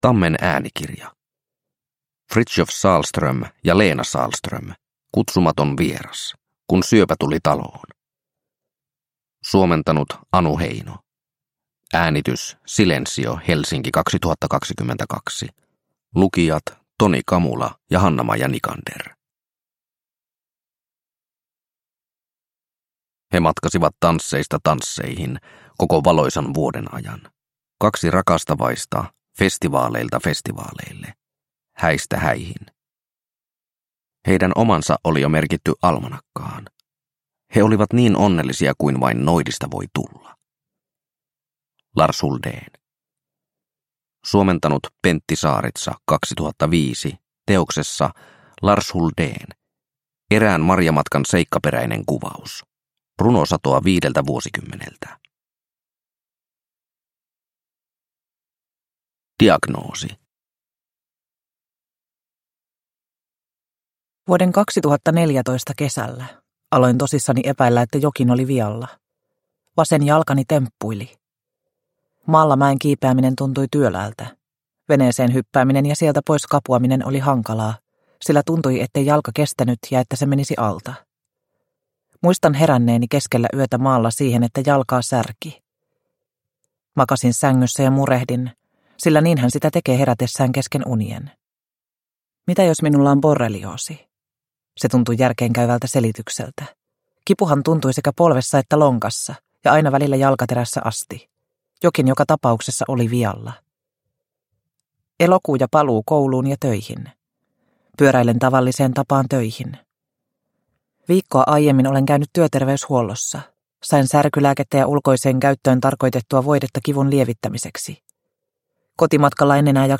Kutsumaton vieras – Ljudbok – Laddas ner